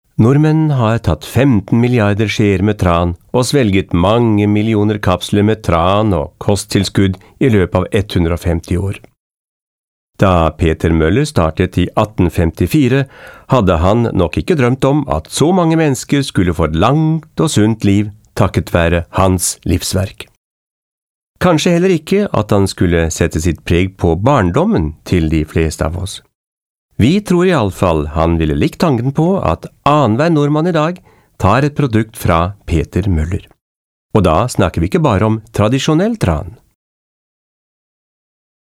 Sprecher Norwegisch.
Kein Dialekt
Sprechprobe: eLearning (Muttersprache):
norvegian voice over artist